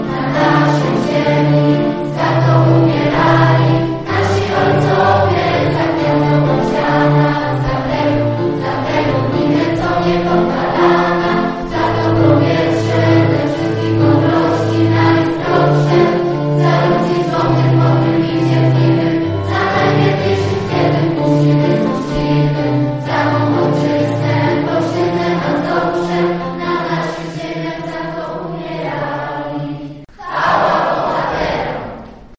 żniński chór męski
1_chor.mp3